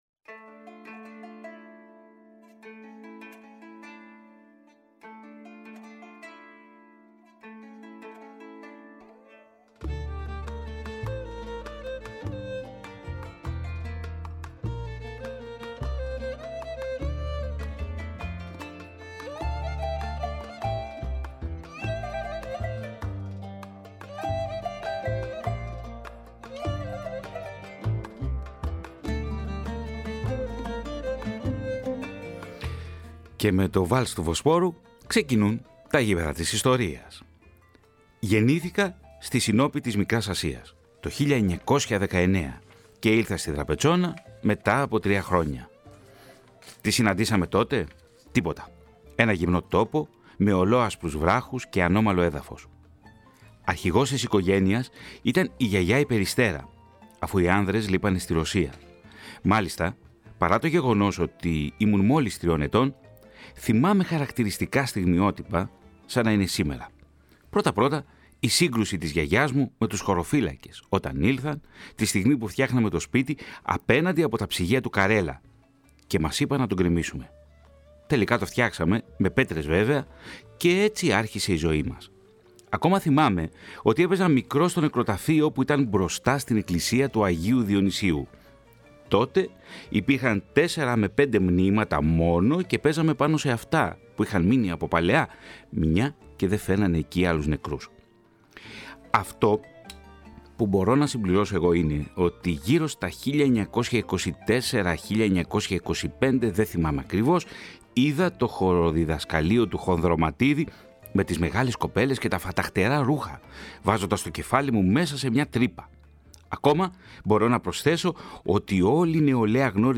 Στο β’ μέρος του ραδιοφωνικού ντοκιμαντέρ για τον αθλητισμό στην προσφυγική Δραπετσώνας ακούγονται οι πολύτιμες προφορικές μαρτυρίες
ΝΤΟΚΙΜΑΝΤΕΡ